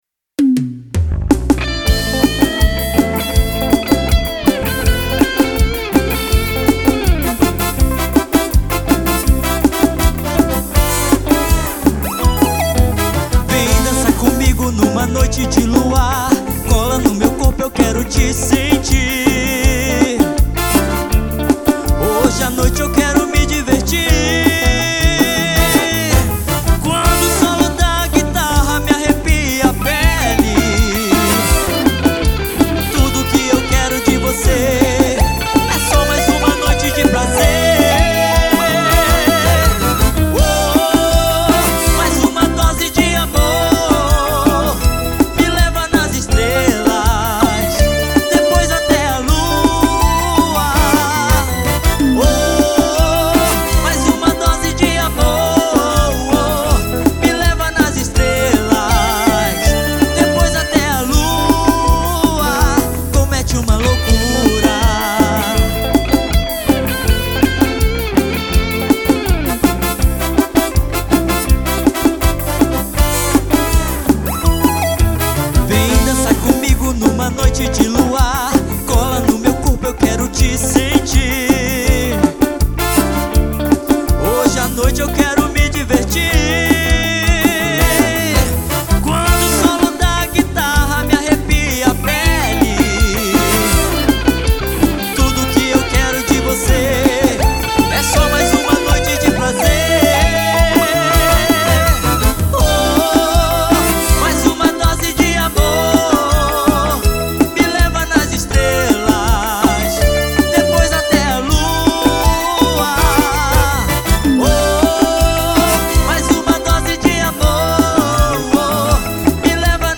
EstiloTecnobrega